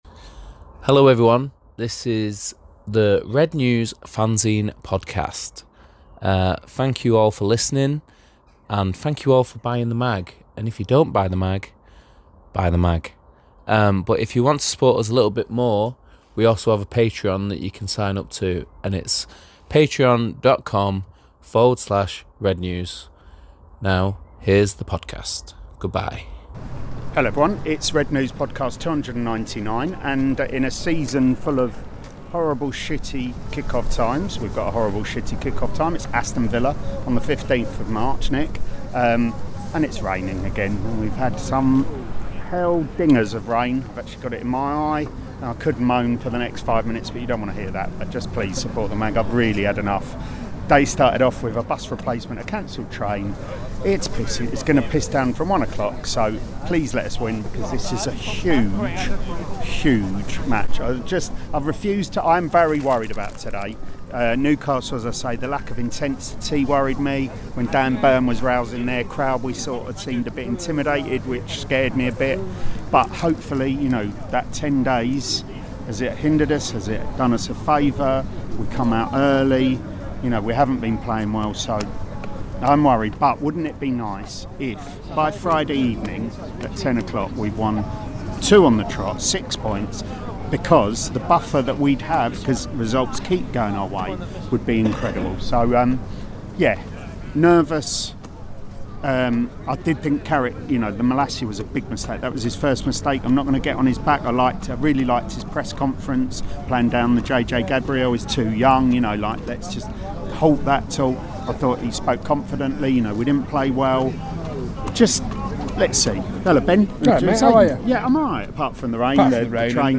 No more need be said!The independent, satirical Manchester United supporters' fanzine - for adults only, contains expletives, talks MUFC, or not at times, as we are very, very giddy after that incredible, late FA Cup victory.